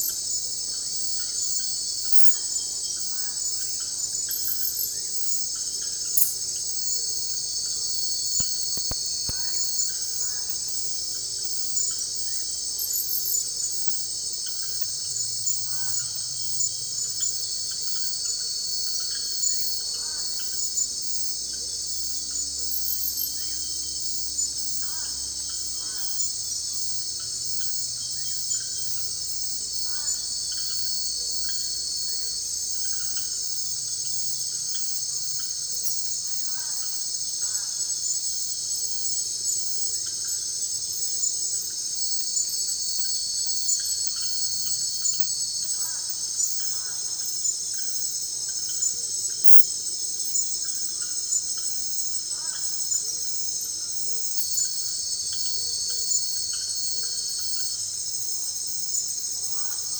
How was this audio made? Finals-Data-Ultrasound